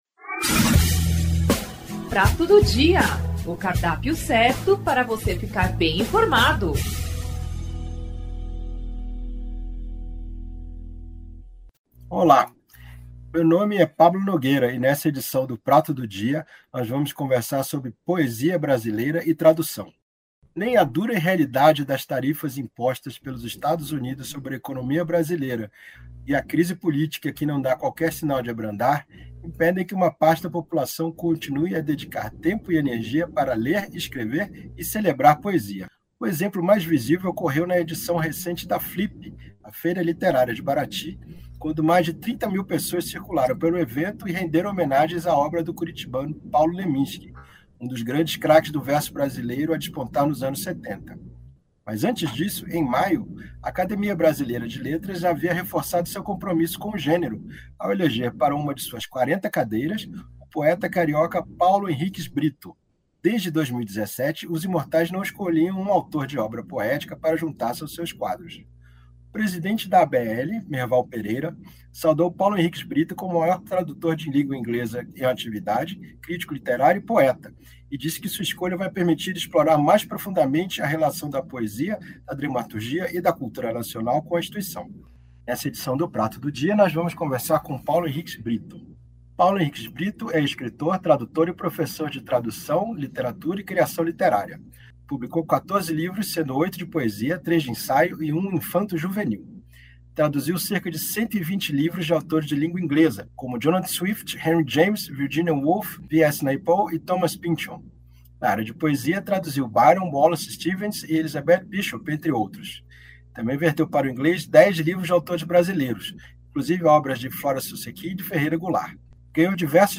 Paulo Henriques Britto é o entrevistado em destaque nesta edição do Podcast Prato do Dia.
O “Prato do Dia”, Podcast da Assessoria de Comunicação e Imprensa da Reitoria da Unesp é um bate-papo e uma troca de ideias sobre temas de interesse da sociedade. De maneira informal debateremos tópicos atuais, sempre na perspectiva de termos o contra-ponto, o diferencial.